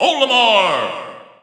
The announcer saying Olimar's name in English releases of Super Smash Bros. 4 and Super Smash Bros. Ultimate.
Olimar_English_Announcer_SSB4-SSBU.wav